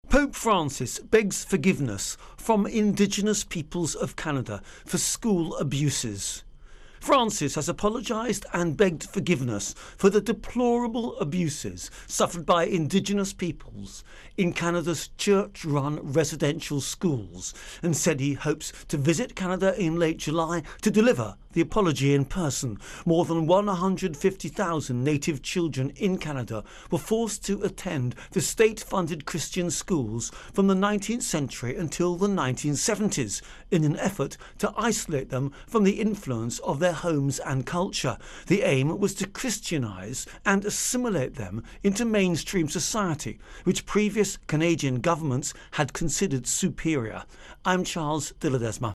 REL Vatican Indigenous Apology Intro and Voicer